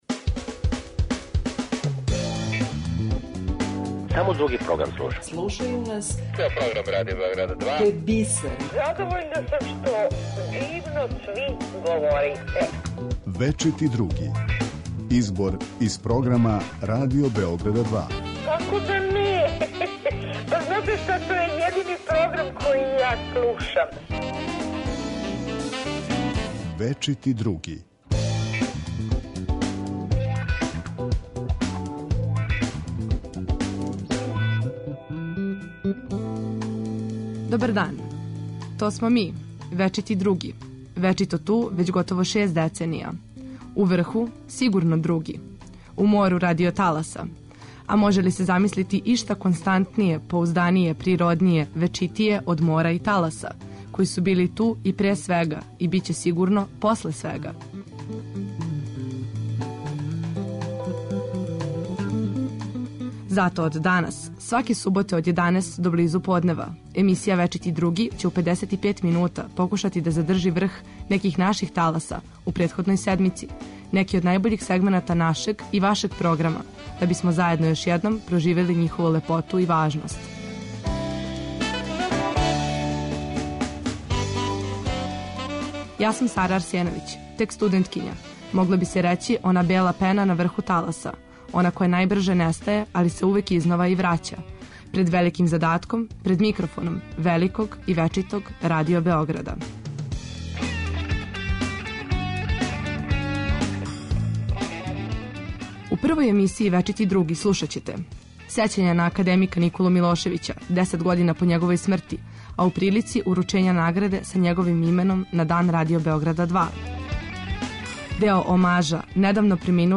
Емисија ће у 55 минута покушати да задржи врх неких наших таласа у претходној седмици, неке од најбољих сегмената нашег - и вашег - програма, да бисмо заједно још једном проживели њихову лепоту и важност.